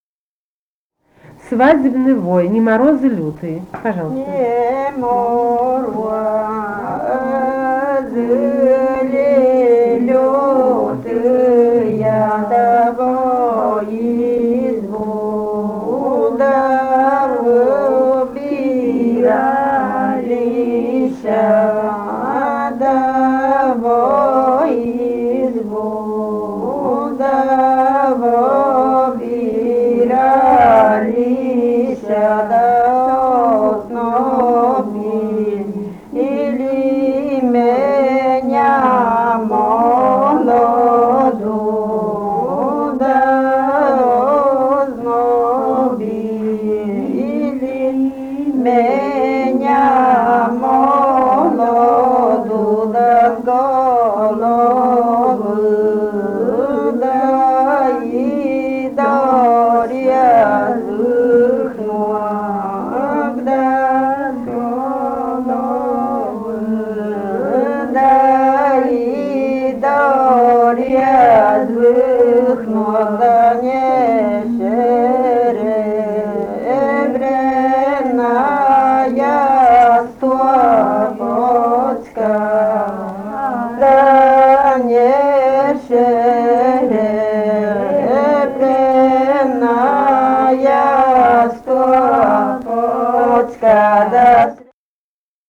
полевые материалы
«Не морозы ли лютыя» (свадебный «вой»).
Костромская область, д. Колодезная Межевского района, 1964 г. И0794-18